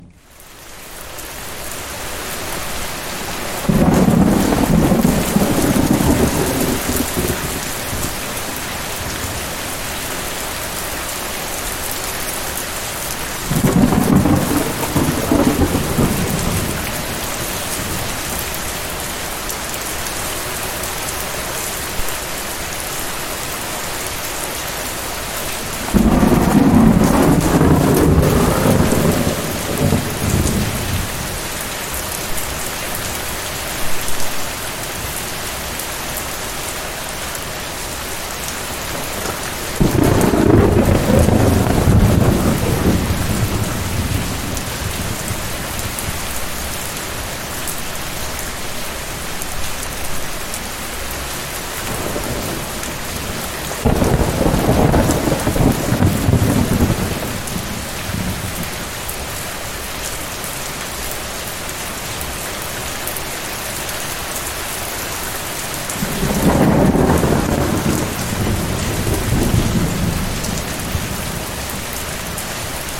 Audio - Calm Rain
Nature Audio - No Distractions only Focus
calm-rain-AQExbl5wrqH9bzZk.mp3